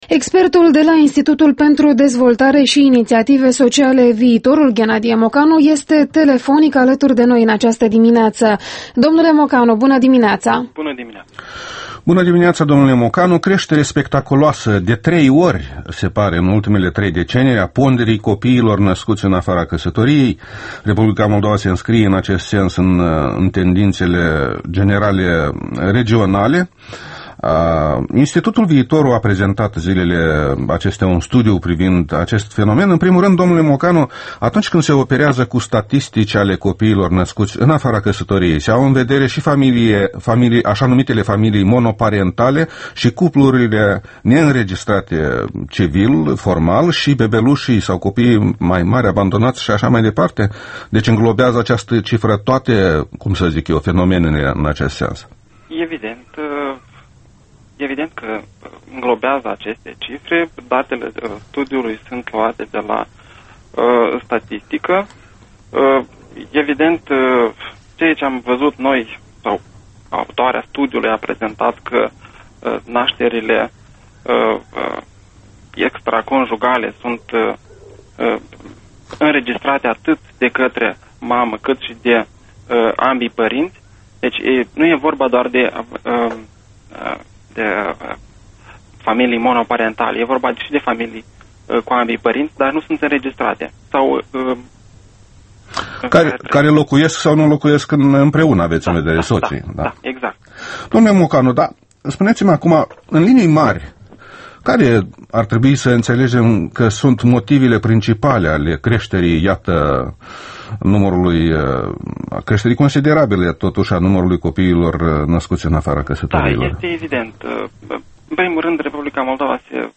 Interviul matinal la Europa Liberă